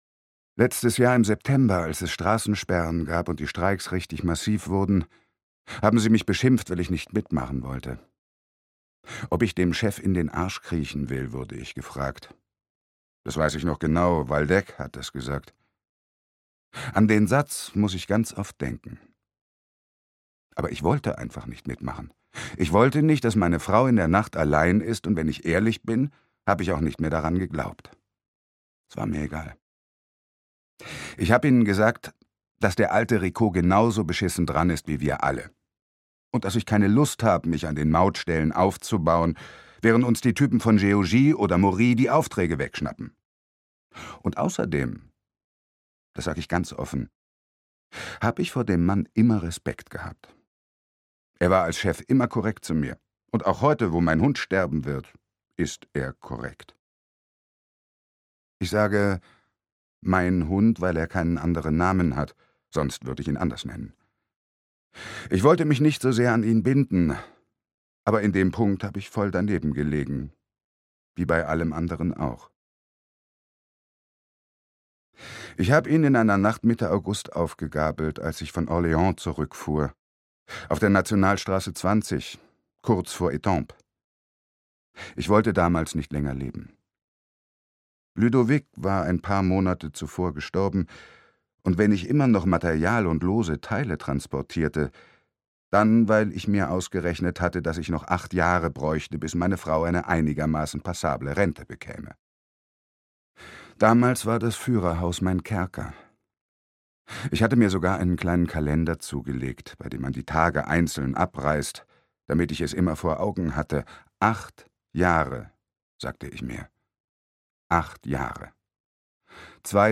Ab morgen wird alles anders - Anna Gavalda - Hörbuch